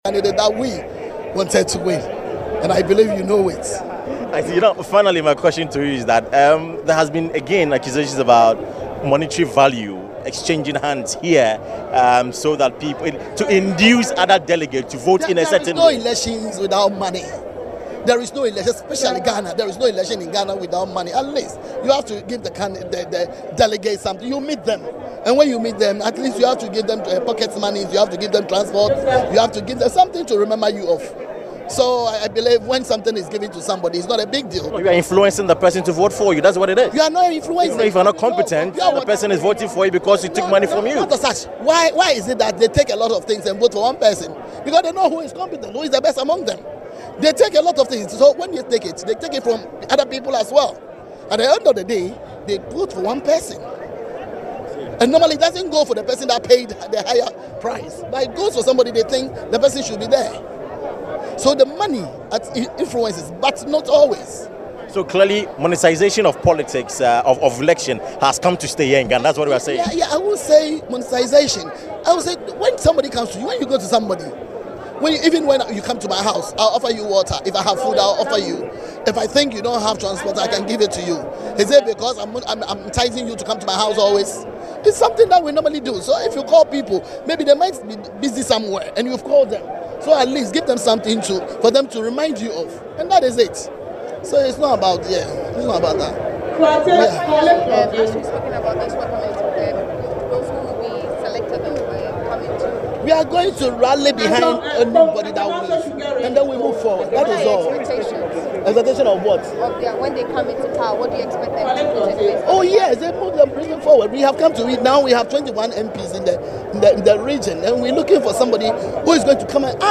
“There is no election without money, especially in Ghana there is no election without money,” the deputy Minister of Health told the media when confronted with the development.